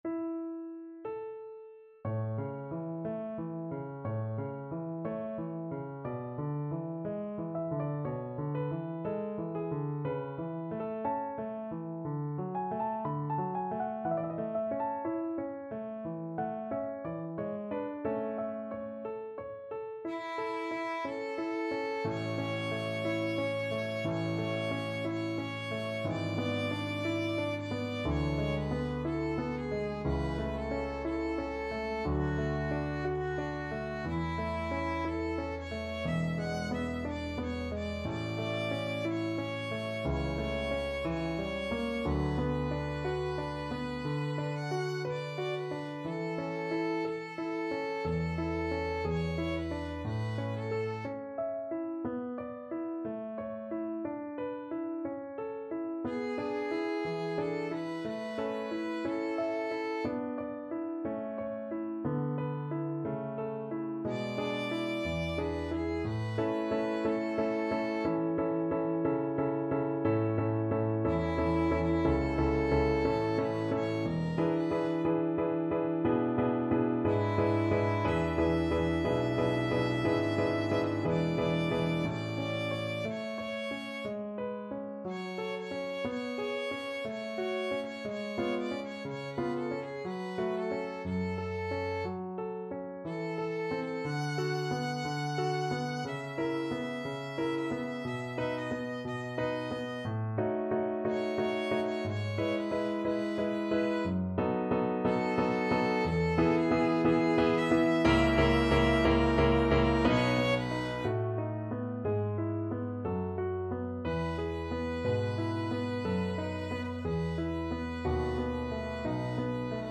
Violin version
4/4 (View more 4/4 Music)
~ = 60 Larghetto
Classical (View more Classical Violin Music)